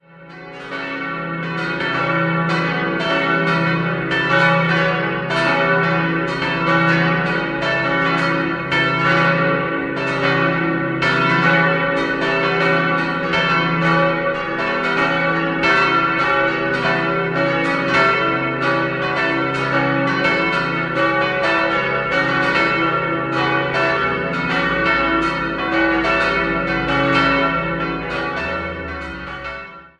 An den insgesamt sieben Altären zeigen sich Einflüsse der Altäre in der Münchner Theatinerkirche. 5-stimmiges Geläut: cis'-e'-fis'-gis'-h' Die kleine Glocke wurde im Jahr 1922 von Karl Hamm in Regensburg gegossen.